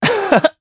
Zafir-Vox_Happy2_jp.wav